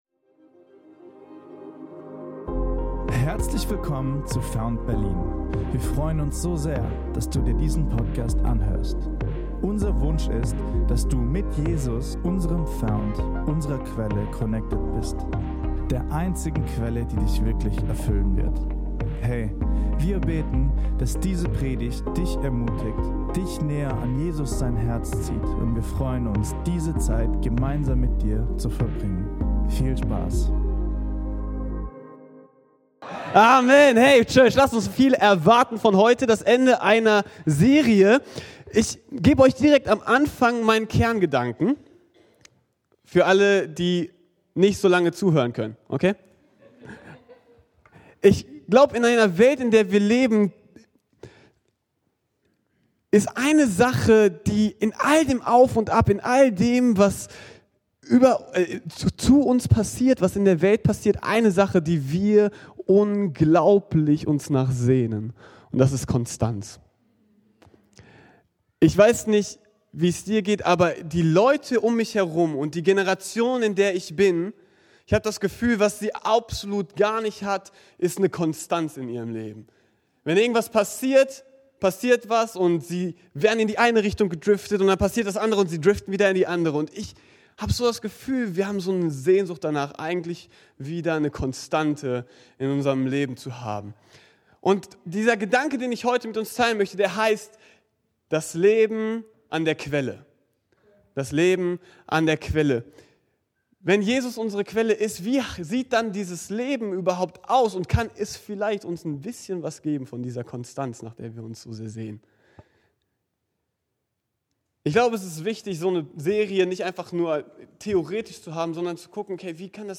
Ist dein Leben geprägt von Höhen und Tiefen und fühlt sich oft an wie eine Achterbahnfahrt? — Erfahre durch diese Predigt, wie Du Konstanz für und in dein Leben bekommst und es dadurch anfängt reiche Früchte zu tragen.